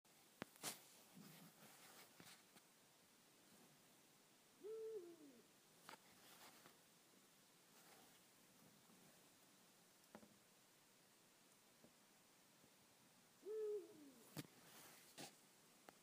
OWL Hooting
Yes, it's quiet, but it's a lovely owl.